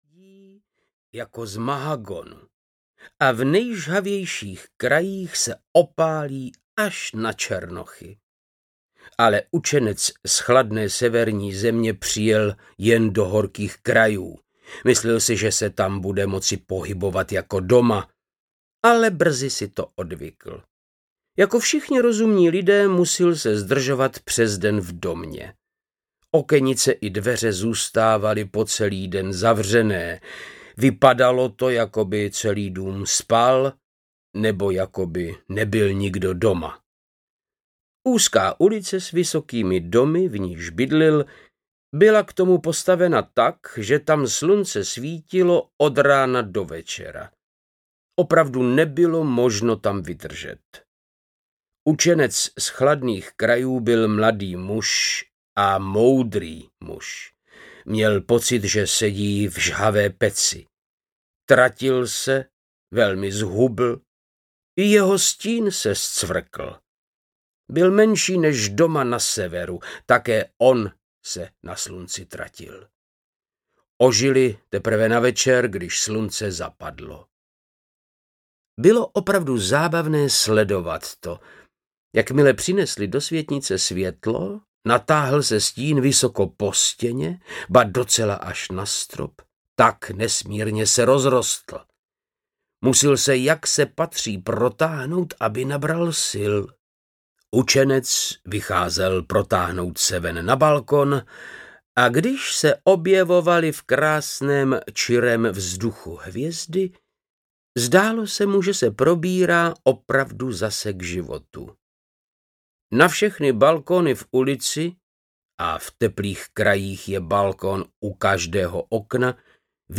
Kytice pohádek audiokniha
Ukázka z knihy
• InterpretVáclav Knop
kytice-pohadek-audiokniha